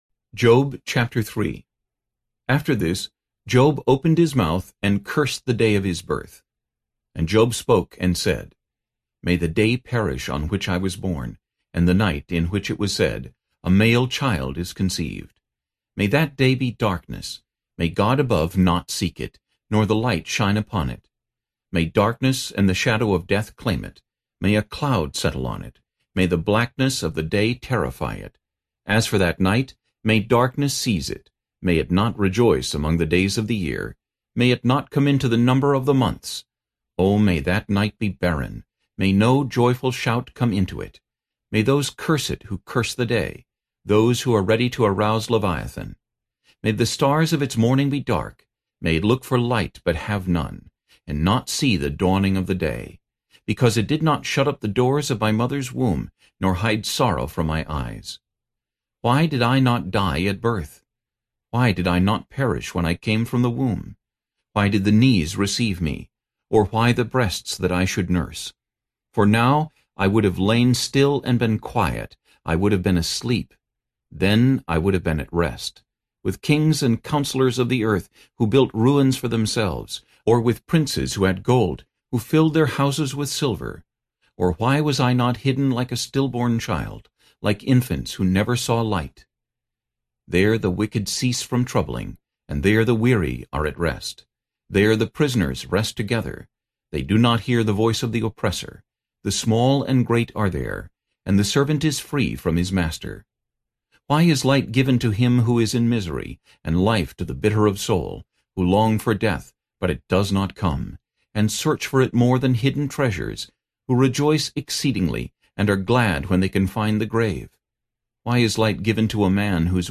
Narrator
1.5 Hrs. – Unabridged
Job NKJV Voice Only TN Sample.mp3